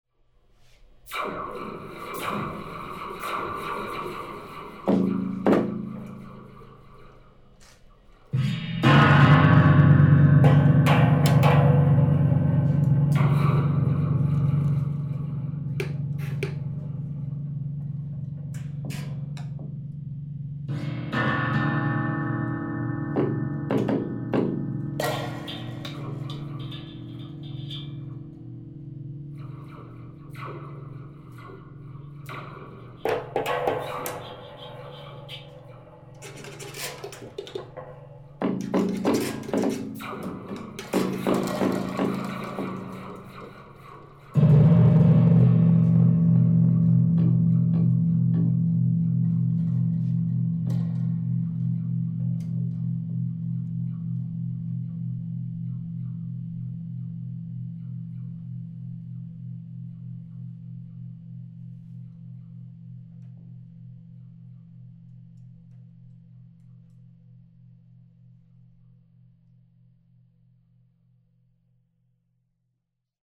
Through listening and touch, amplified springs allowed participants opportunities to practice listening exercises, as well as create their own short, sound-based pieces using both improvisational and compositional techniques.